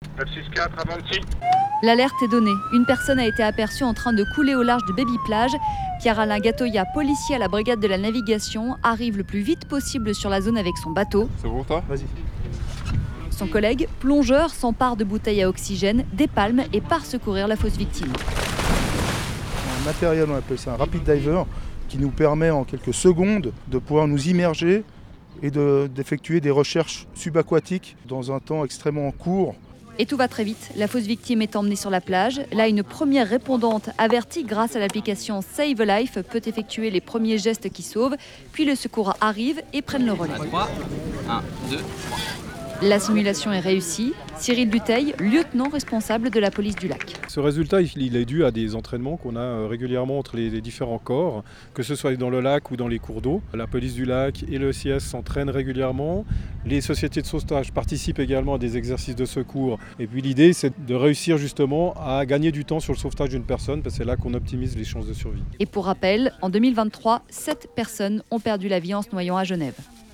Reportage lors de l’une d’elles: